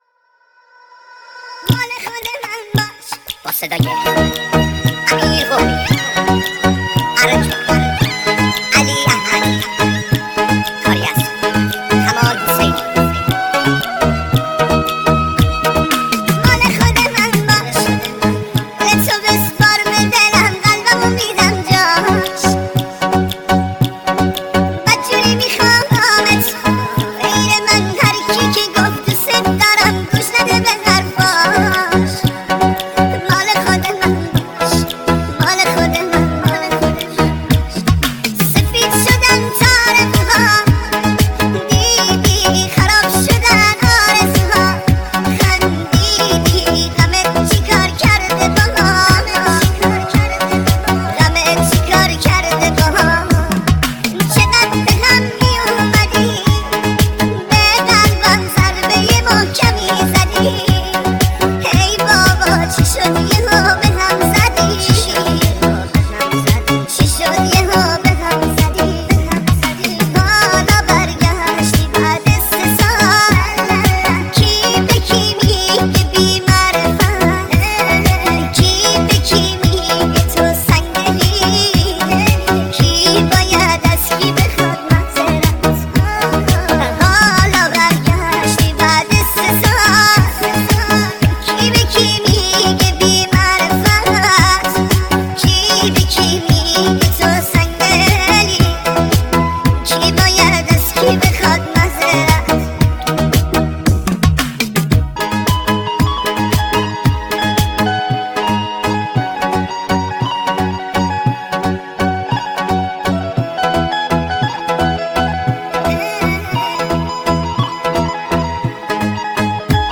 ژانر: پاپ
ورژن ریمیکس با صدای نازک شده پسر بچه